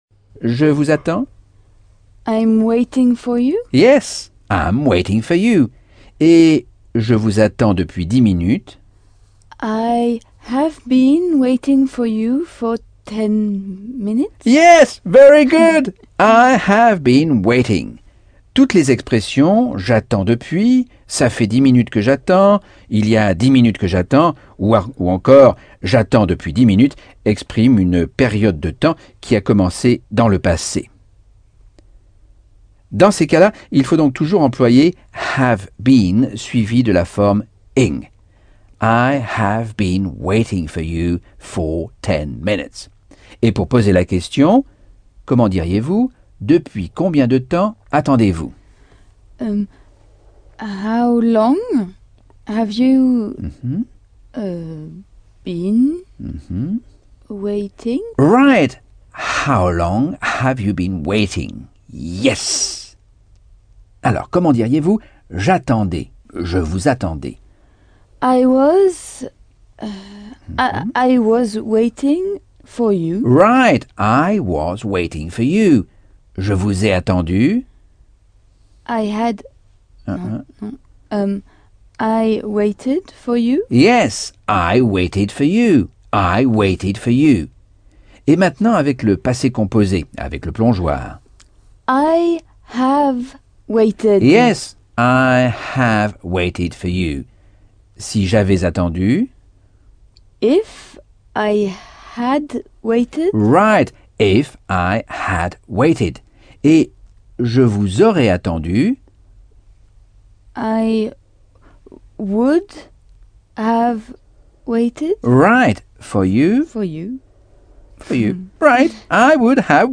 Leçon 7 - Cours audio Anglais par Michel Thomas - Chapitre 9